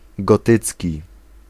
Ääntäminen
Synonyymit opus francigenum Ääntäminen France: IPA: [ɡɔ.tik] Haettu sana löytyi näillä lähdekielillä: ranska Käännös Ääninäyte 1. gotycki Suku: m .